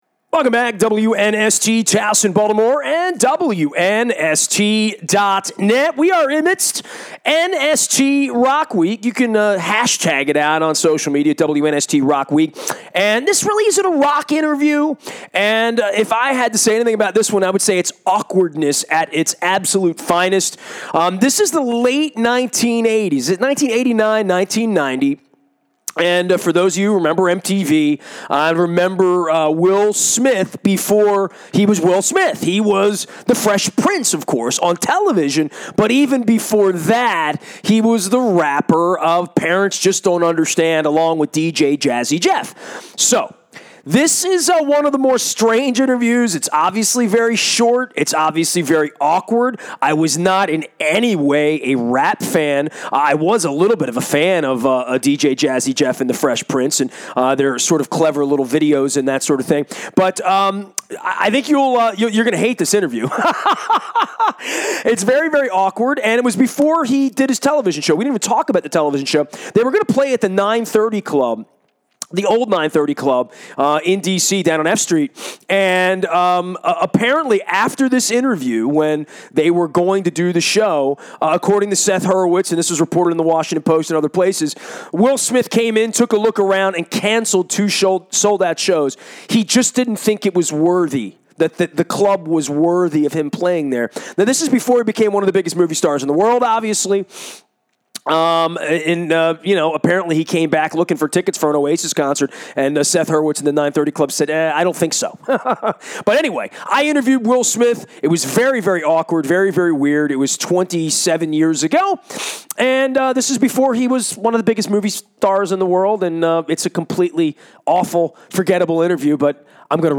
The conversation, which took place before Smith’s television show and movie stardom, was awkward and brief. They discussed their upcoming tour, the intimate nature of their shows, and their audience demographics, which were mainly younger black and white kids.
Will Smith, Fresh Prince, DJ Jazzy Jeff, awkward interview, 930 club, canceled show, young audience, rap industry, Grammy performance, Mike Tyson song, new material, theatrical show, tour plans, intimate concert, rap audience